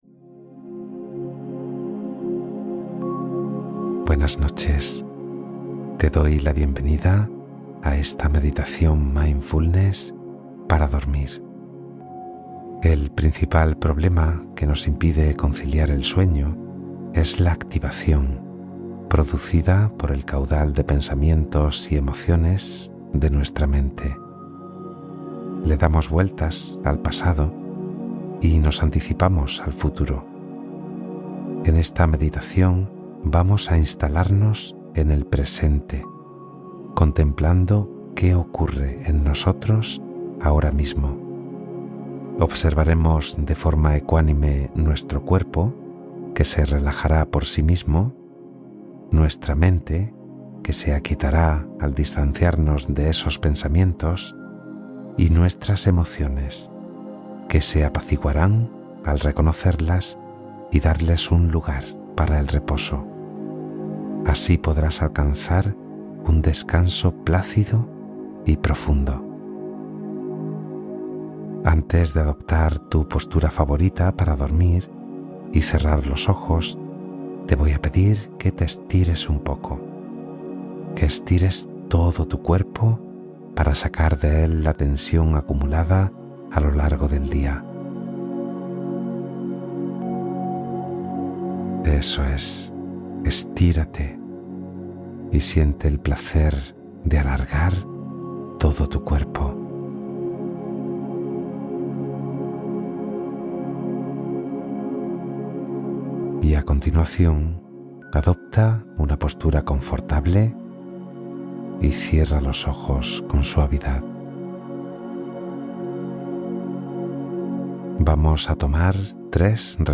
Meditación mindfulness para dormir: cuerpo, mente y emociones equilibrados